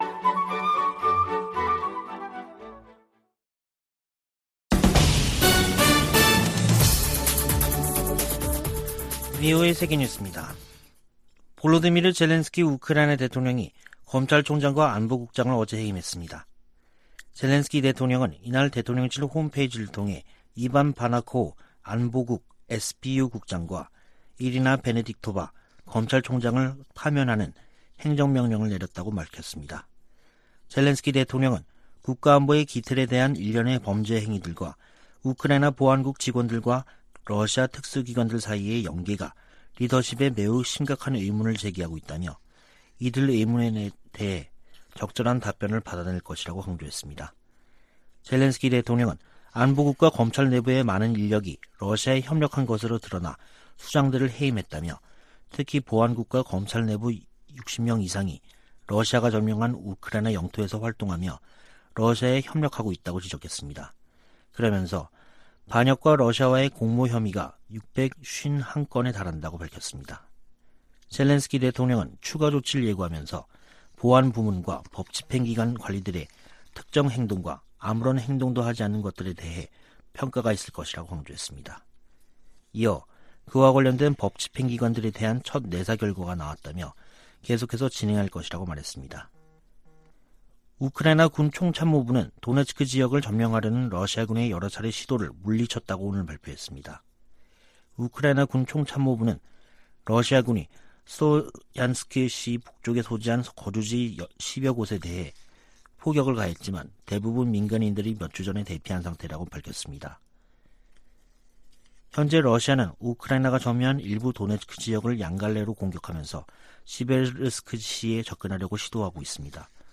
VOA 한국어 간판 뉴스 프로그램 '뉴스 투데이', 2022년 7월 18일 2부 방송입니다. 미 국무부 고위 관리가 정보기술(IT) 분야에 위장 취업한 북한인들과 제3국인들이 제기하는 문제와 위험성을 경고했습니다. 워싱턴 전문가들은 북한의 제재 회피를 방조하는 중국과 러시아를 제재할 필요가 있다고 강조했습니다. 주한미군 규모를 현 수준으로 유지하는 새 회계연도 국방수권법안을 미 하원이 최종 의결했습니다.